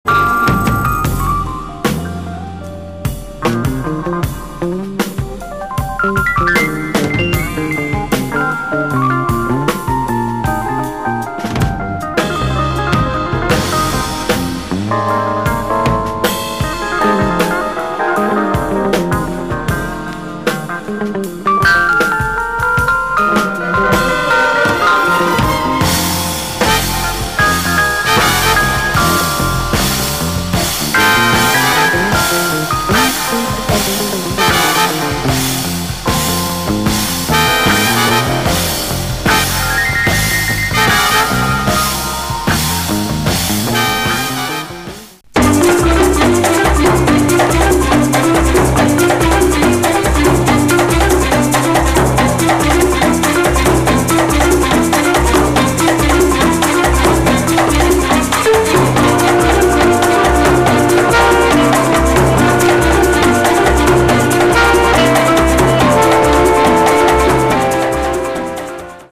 Very rare French soundtrack !
latin jazz
sensual jazz groove
fender Rhodes